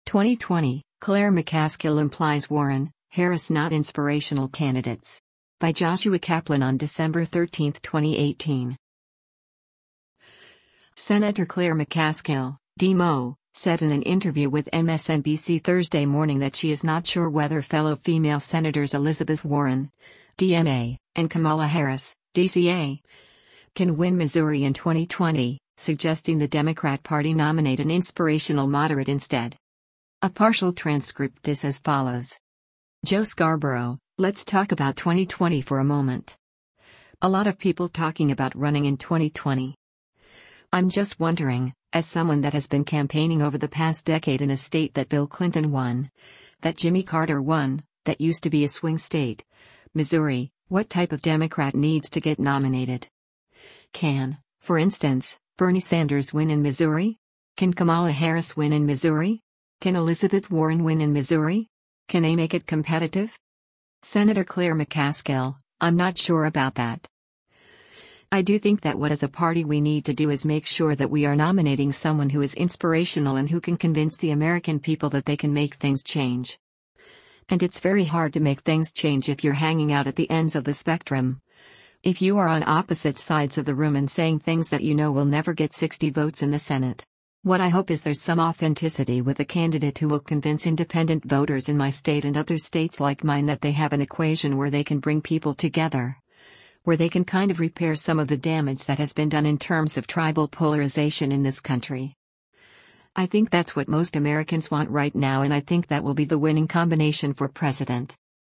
Sen. Claire McCaskill (D-MO) said in an interview with MSNBC Thursday morning that she is “not sure” whether fellow female Sens. Elizabeth Warren (D-MA) and Kamala Harris (D-CA) can win Missouri in 2020, suggesting the Democrat Party nominate an “inspirational” moderate instead.